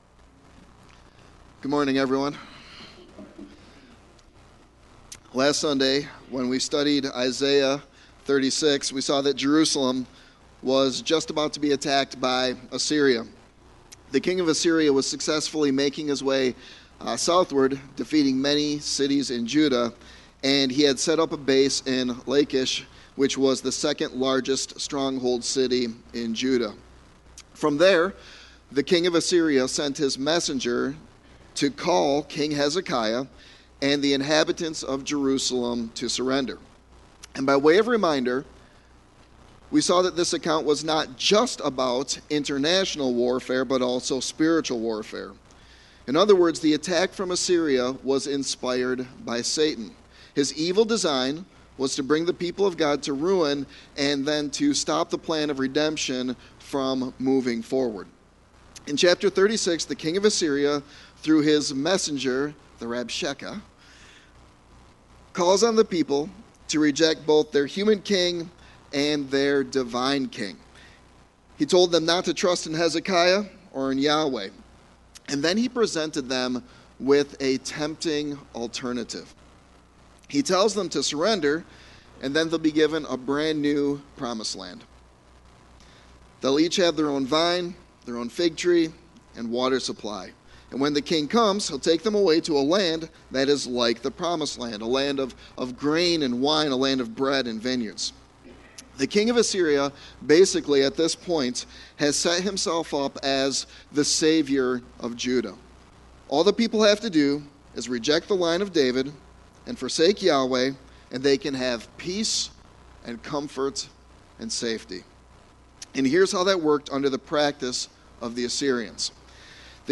Sermon Text